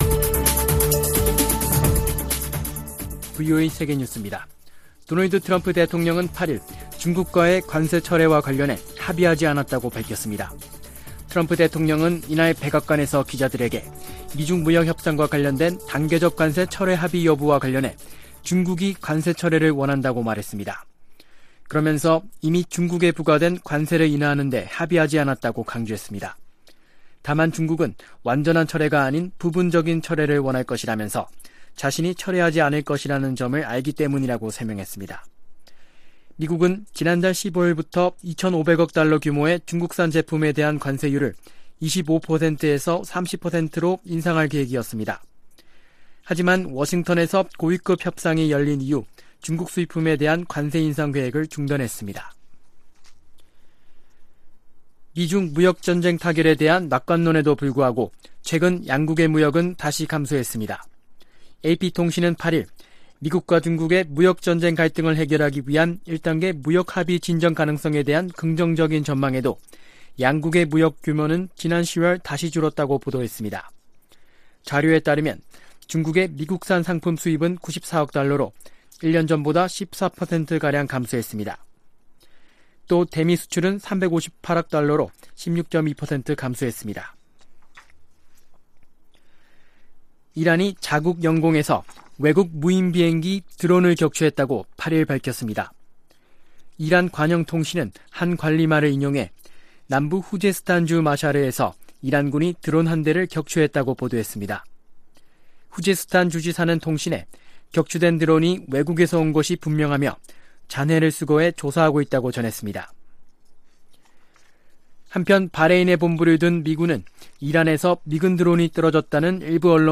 VOA 한국어 아침 뉴스 프로그램 '워싱턴 뉴스 광장' 2019년 11월 9일 방송입니다. 미국 국방부는 다음달 예정된 한국과의 연례 연합공중훈련이 과거에 비해 줄어든 규모가 될 것이라고 밝혔습니다. 미국 국방부가 계속된 탄도미사일과 핵무기 기술 개발이 미국과 동맹국에 위협이 되고 있다며 북한을 2020년 ‘최우선 관리 과제국’에 포함시켰습니다.